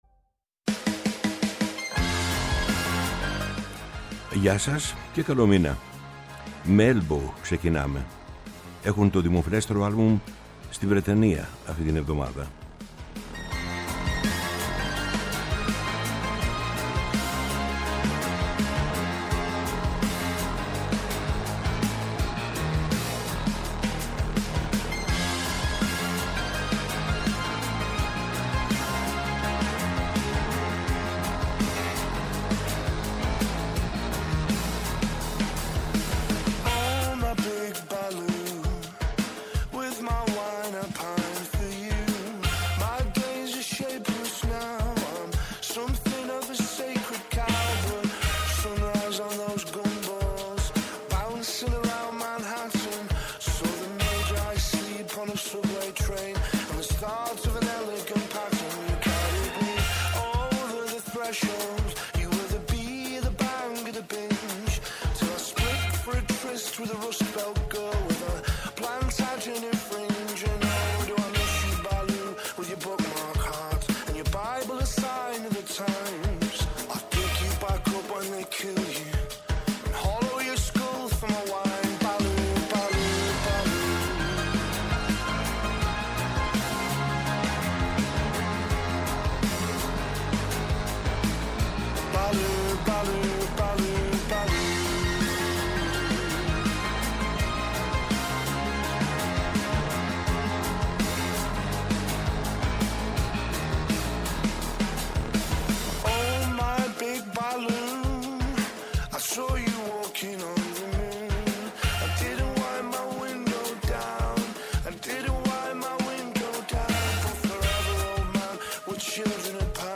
Θυμόμαστε το μεγαλύτερο ροκ συγκρότημα στον πλανήτη
ΜΟΥΣΙΚΗ